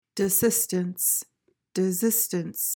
PRONUNCIATION:
(di-SIS/ZIS-tuhns)